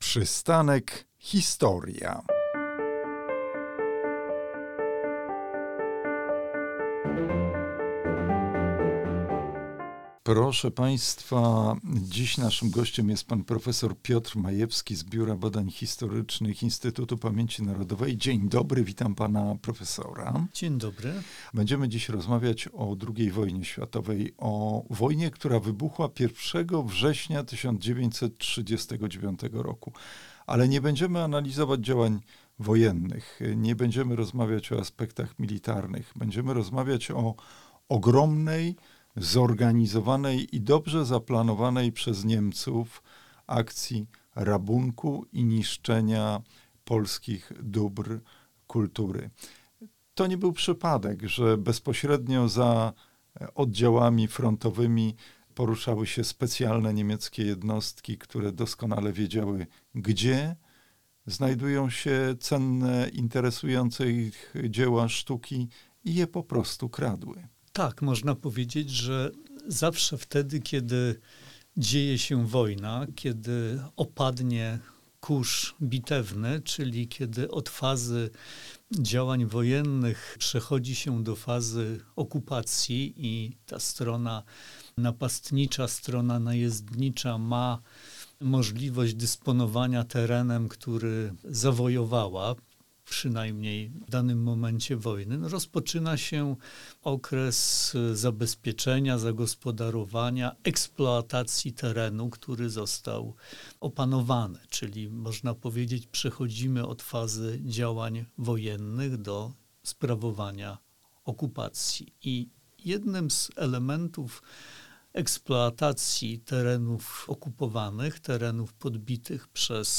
Niezabliźniona rana – dobra kultury zrabowane przez Niemców. Rozmowa